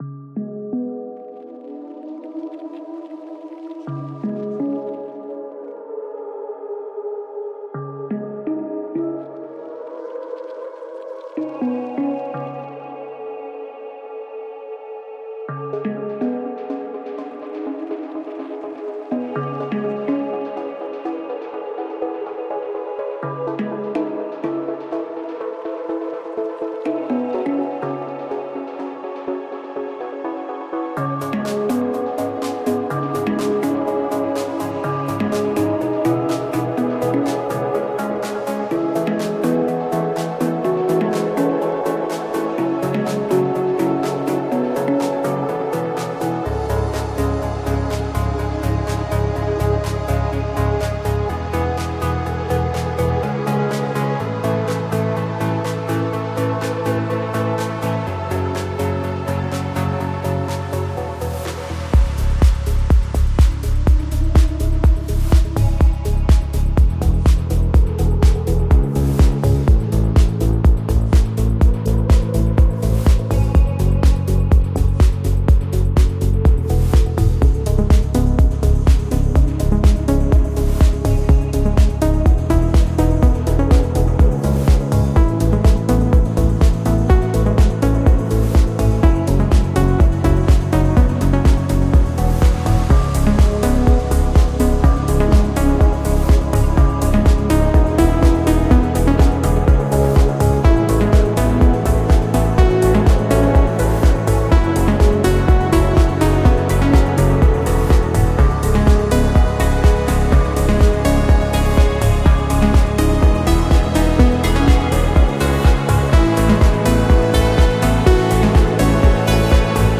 Genre: Melodic Techno